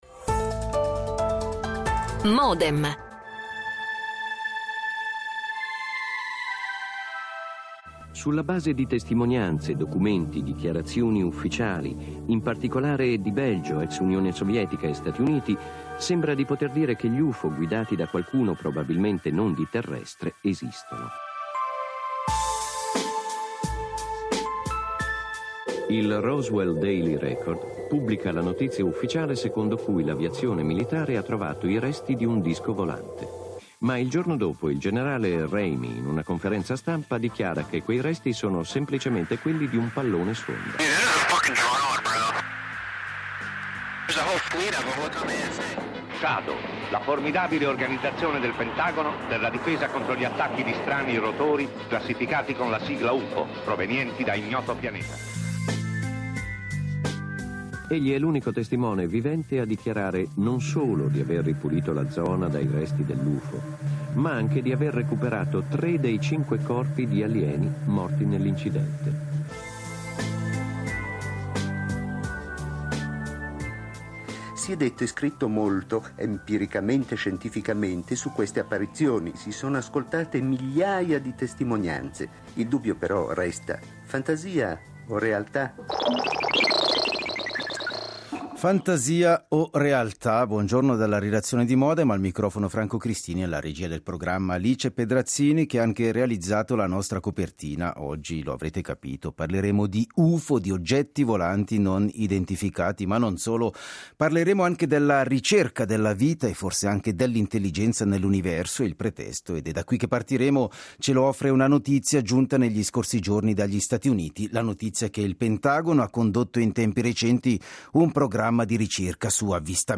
Una discussione che rilancia la tematica della vita nello spazio: siamo davvero soli nell'universo? Come è cambiata la percezione di altre forme di vita nell'universo con la scoperta degli esopianeti?